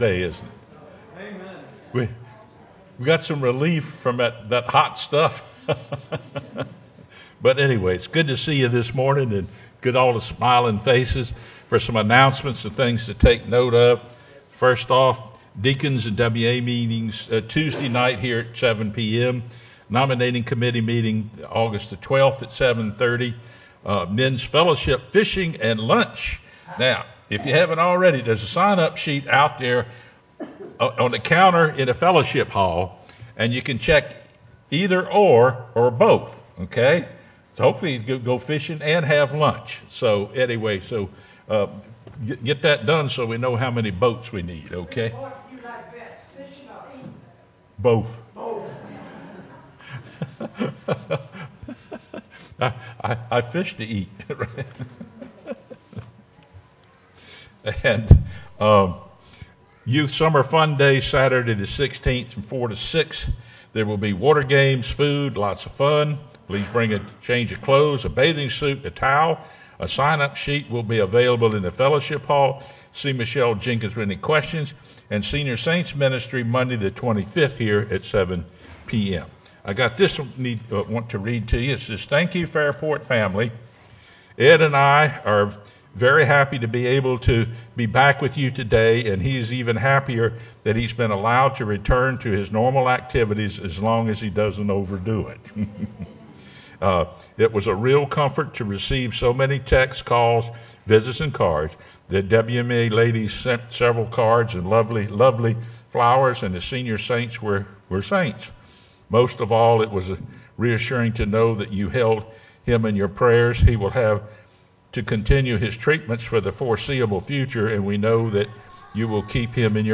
sermonAug03-CD.mp3